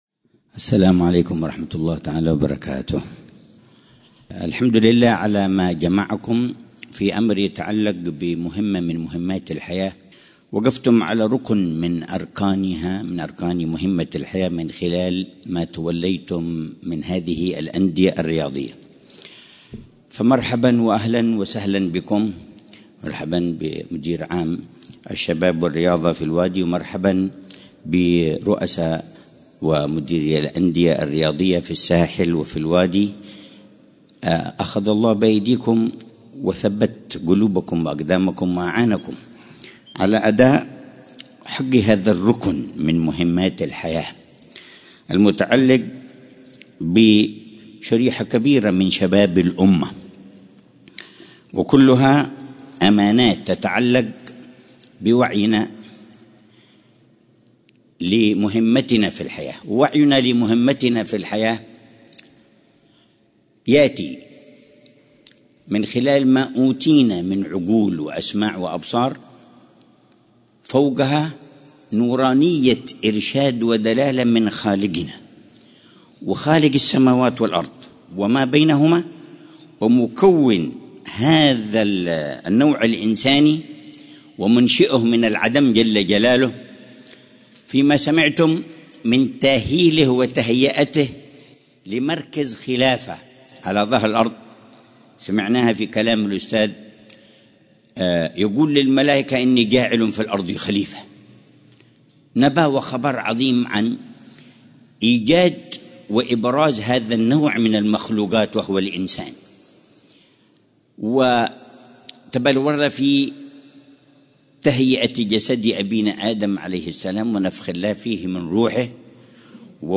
محاضرة العلامة الحبيب عمر بن حفيظ في الجلسة الختامية للملتقى الثاني لإدارات الأندية الرياضية بوادي حضرموت وشخصيات رياضية من الساحل، بعنوان: